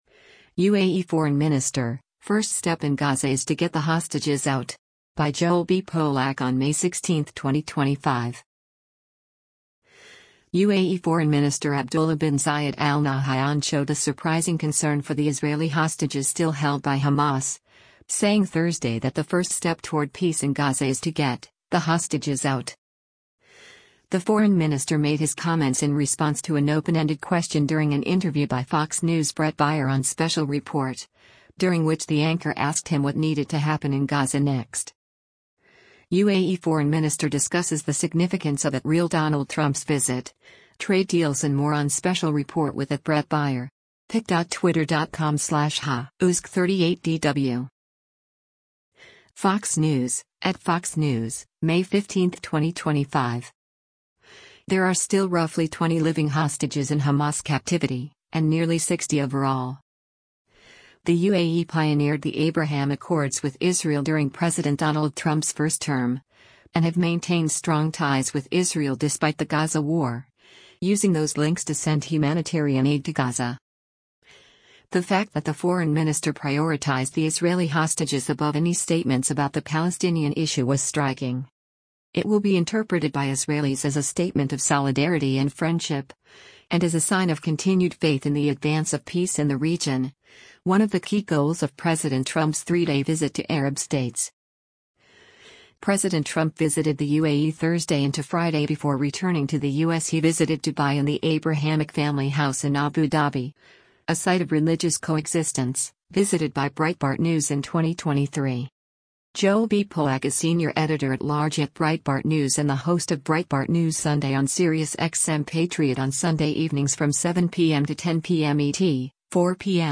The foreign minister made his comments in response to an open-ended question during an interview by Fox News’ Bret Baier on Special Report, during which the anchor asked him what needed to happen in Gaza next.